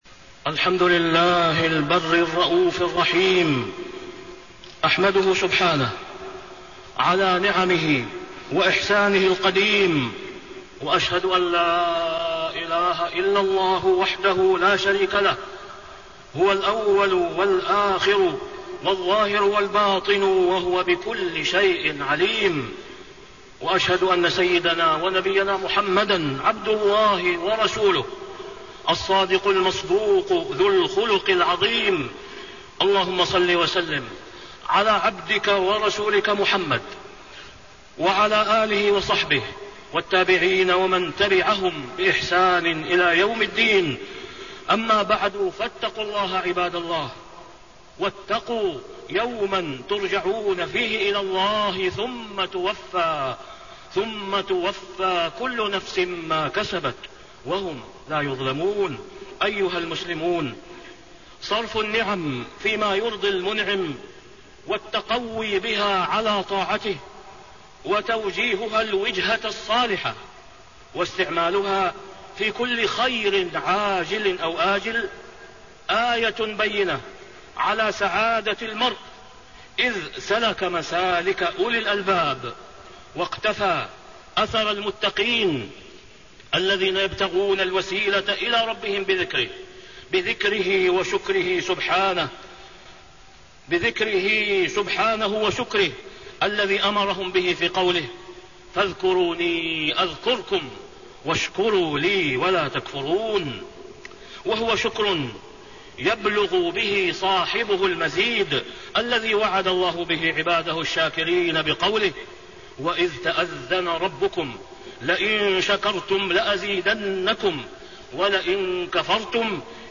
تاريخ النشر ٢٦ ربيع الثاني ١٤٣٤ هـ المكان: المسجد الحرام الشيخ: فضيلة الشيخ د. أسامة بن عبدالله خياط فضيلة الشيخ د. أسامة بن عبدالله خياط من فقه العبد شكر النعم